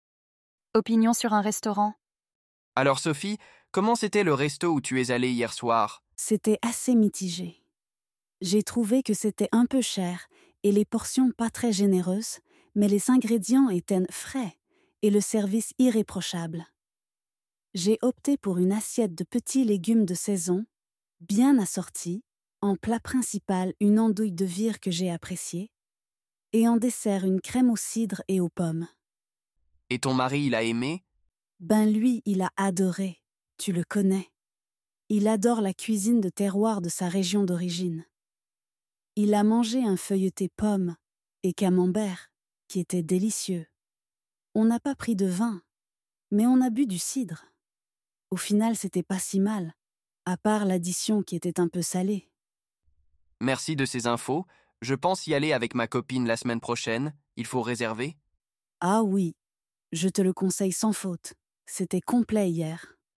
Dialogues en Français